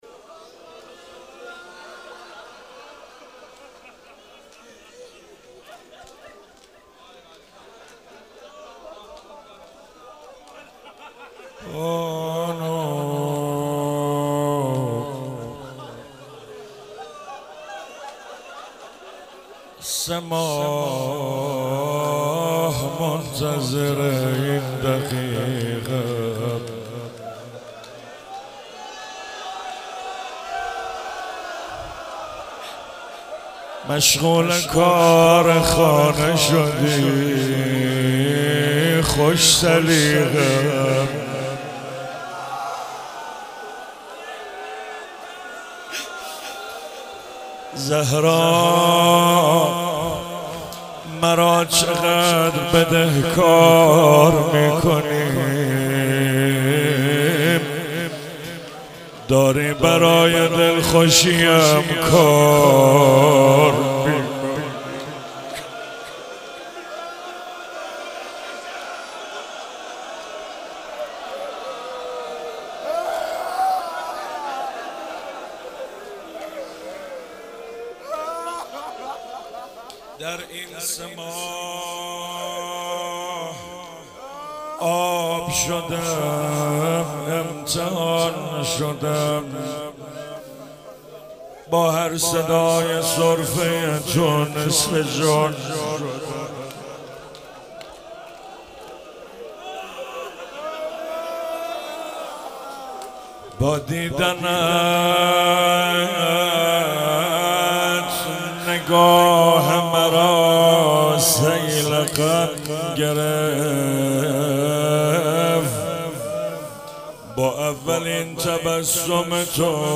خیمه حضرت فاطمه زهرا سلام الله علیها
فاطمیه95 - روضه -بانو سه ماه منتظر این دقیقه ام